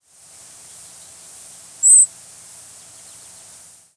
Grasshopper Sparrow diurnal flight calls